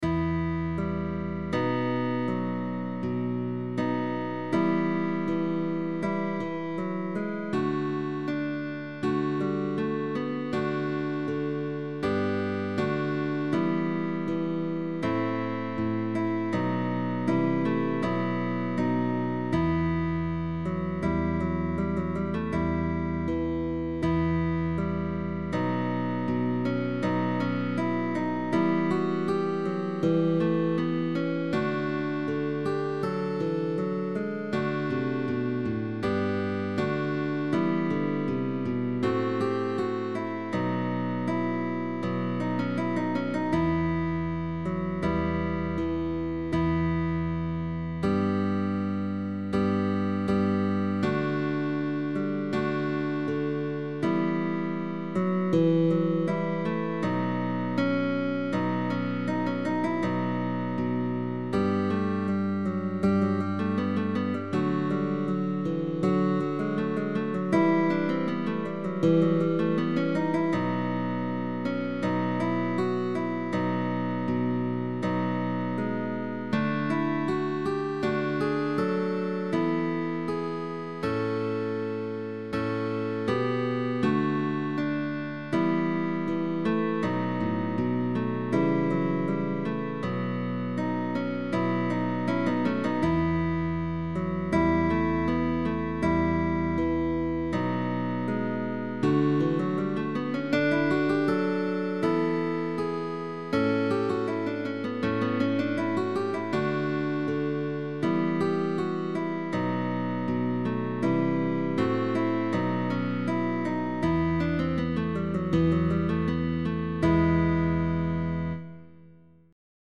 GUITAR QUARTET
Early music